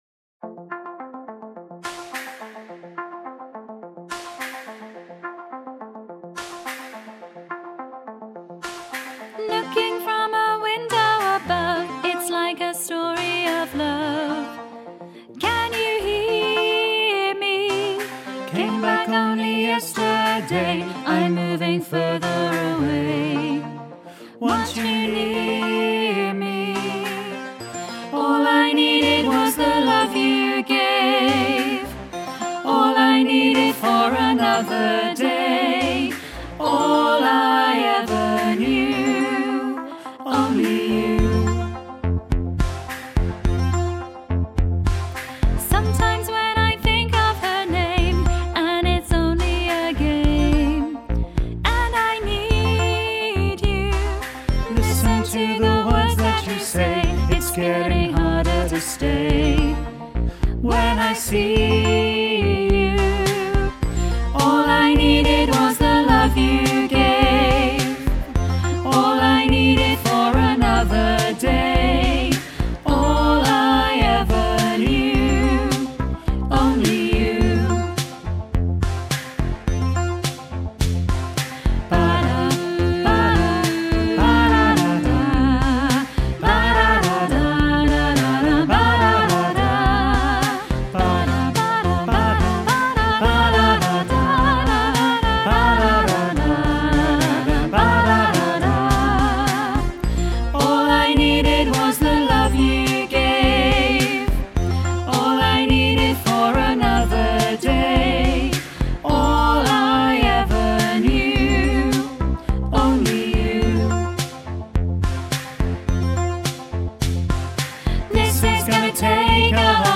4-full-mix-only-you.mp3